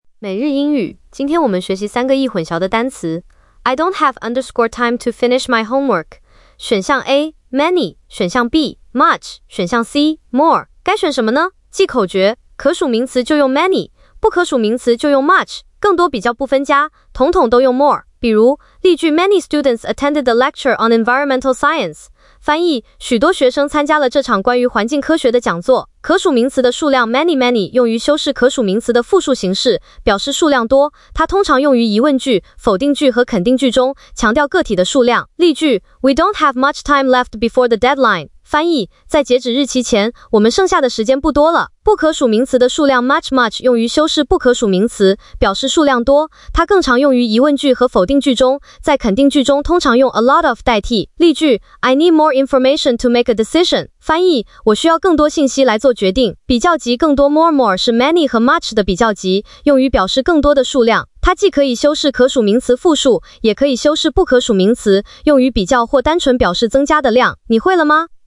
🎧 语音讲解